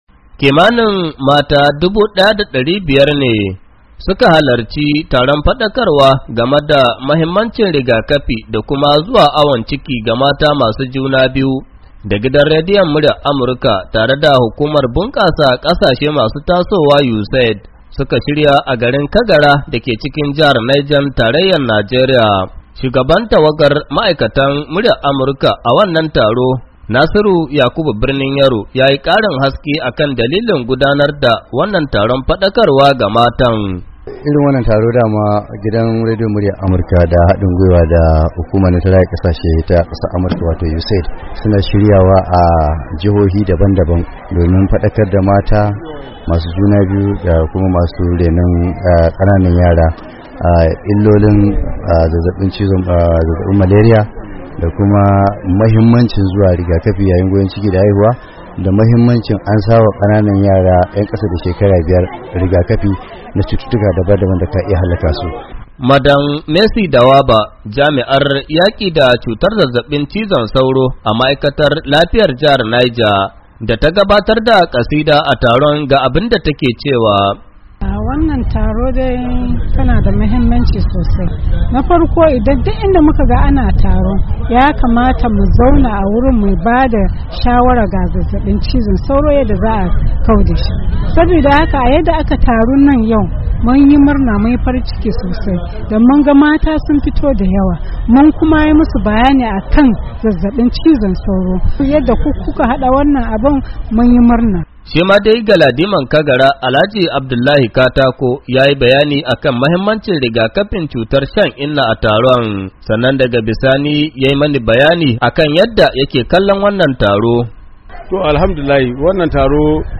WASHINGTON D.C —